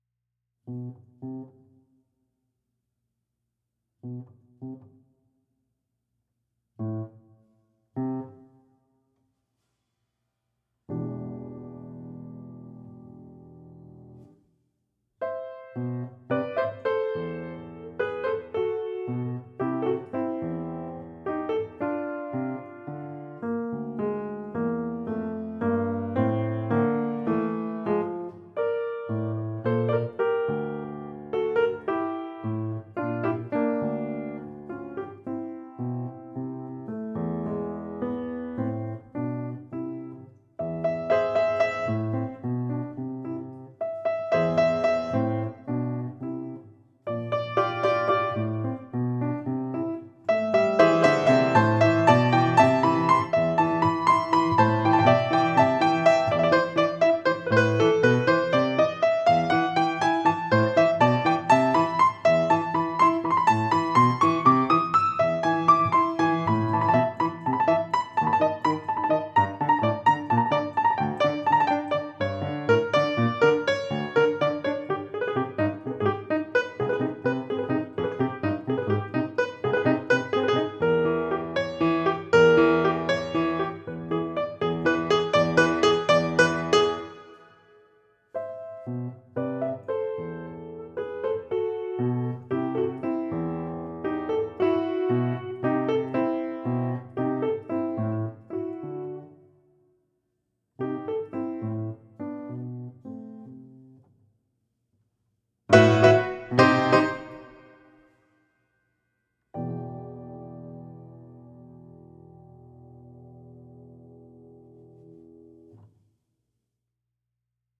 Piano, Música pedagogica